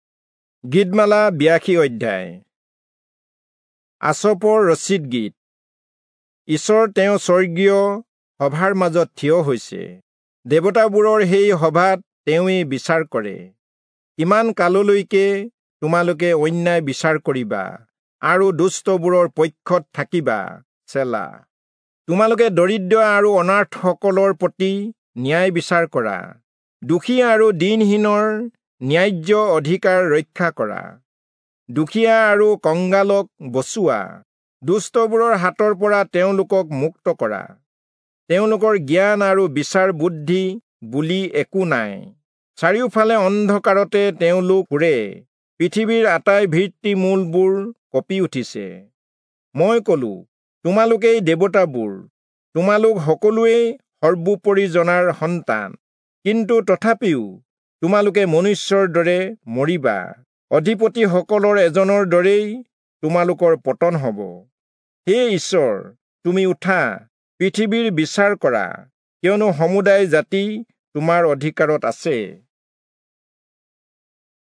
Assamese Audio Bible - Psalms 130 in Bnv bible version